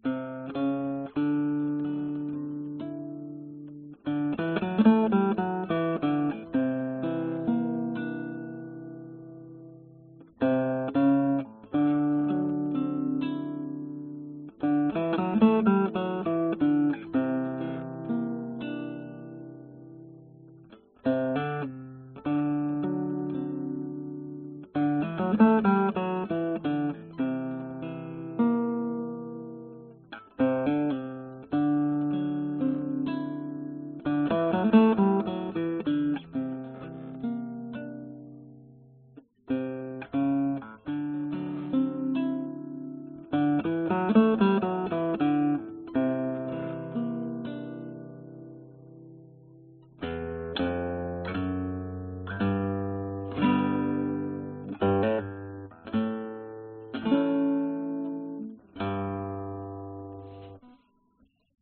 Synthsounds " Bellpad
描述：A bellpad声音，4个C音从C1C7采样
标签： 合成器 仪表
声道立体声